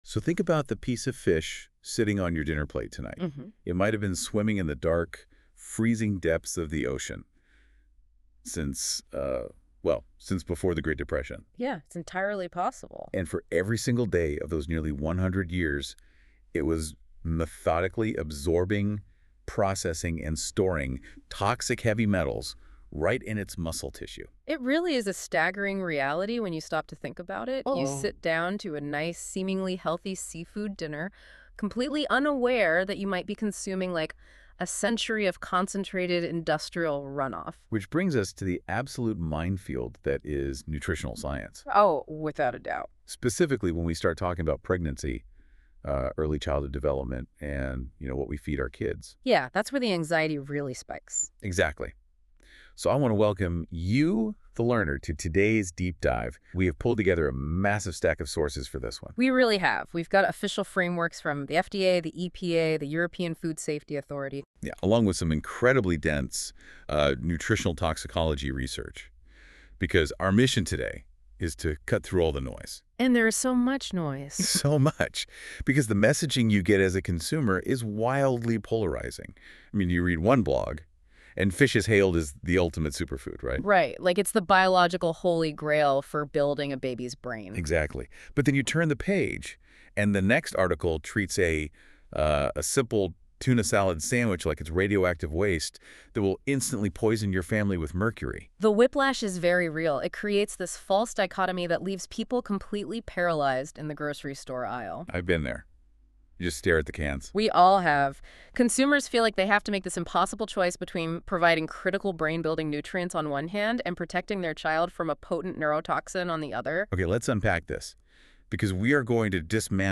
AI Podcast about this article by NotebookLM Download MP3 Disclaimer This article is for general informational purposes only.